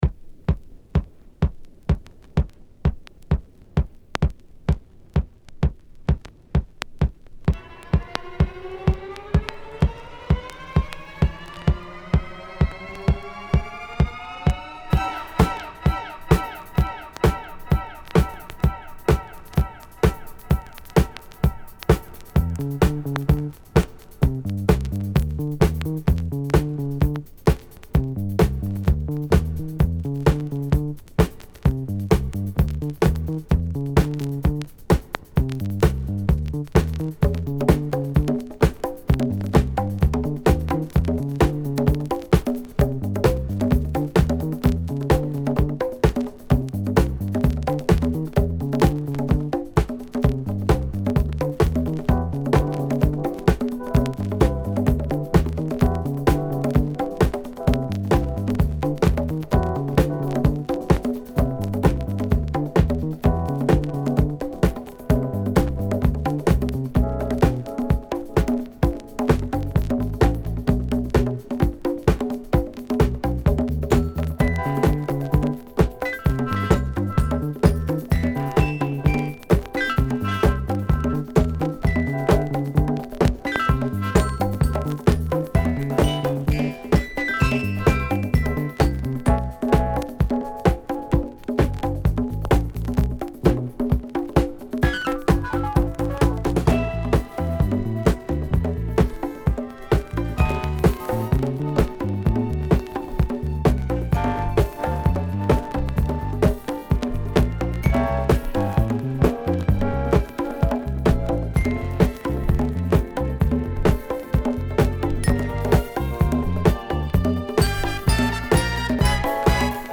Disco~Garage Classic!!